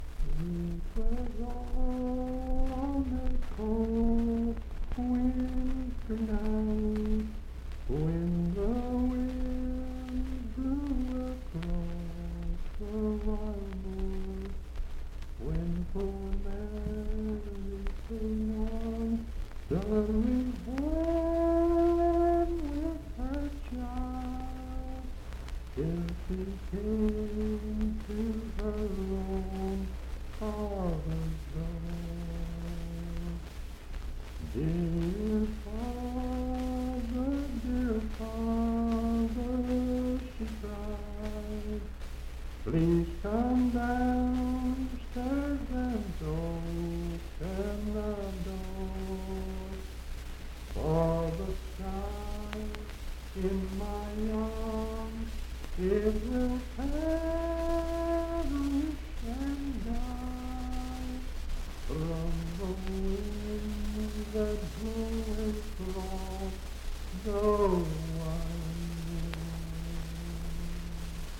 Unaccompanied vocal music
Verse-refrain 2(4).
Voice (sung)
Pocahontas County (W. Va.)